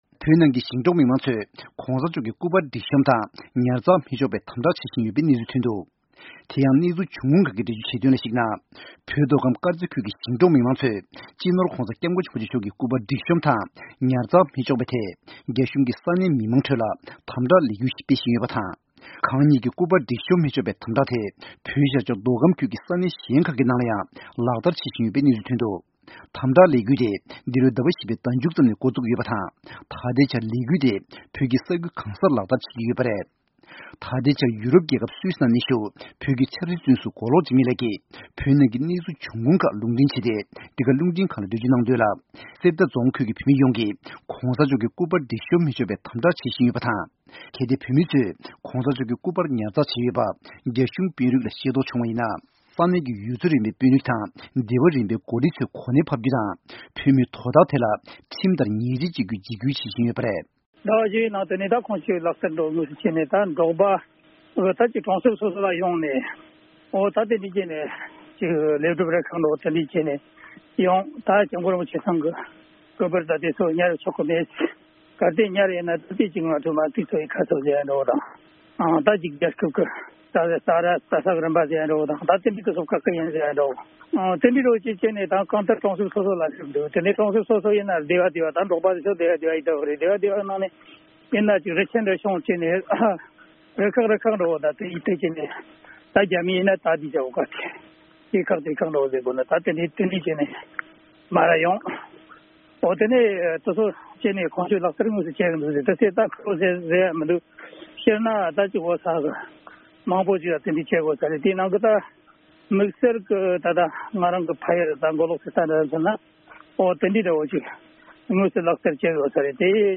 གནས་ཚུལ་སྙན་སྒྲོན་ཞུ་ཡི་རེད།།